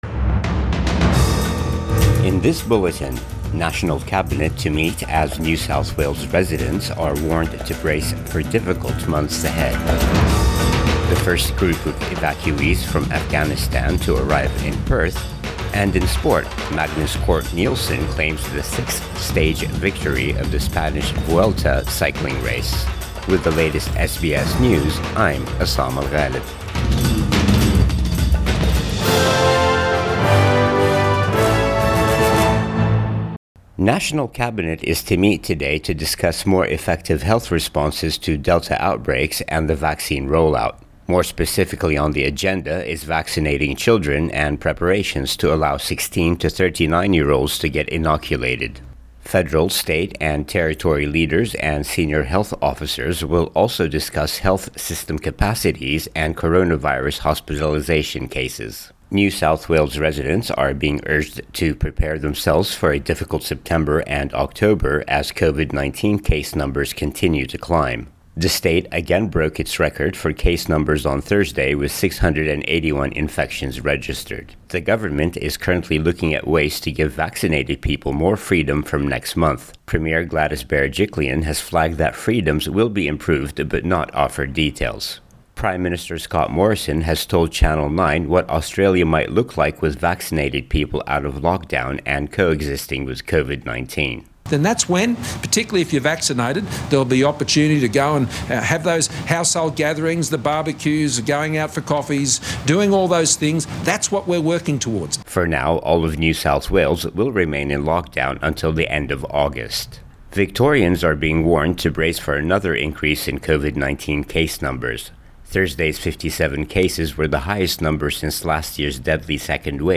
AM bulletin 20 August 2021